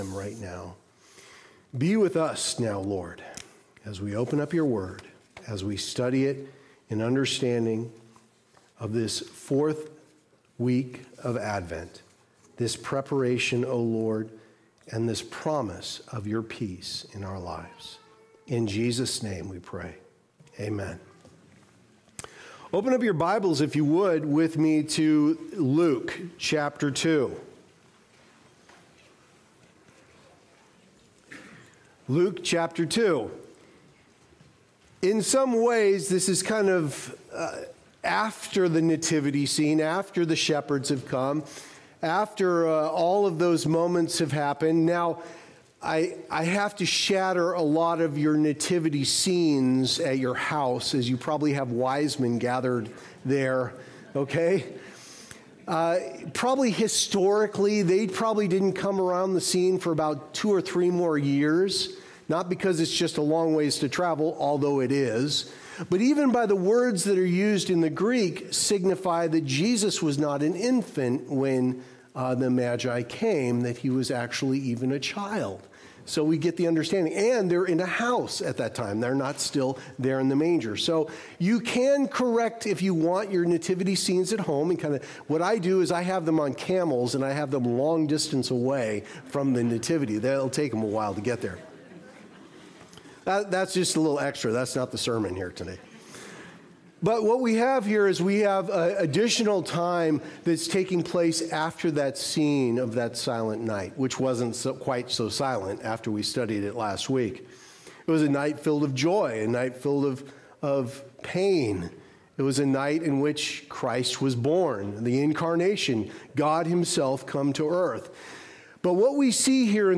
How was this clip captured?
Passage: Luke 2:22-35 Services: Sunday Morning Service Download Files Notes Previous Next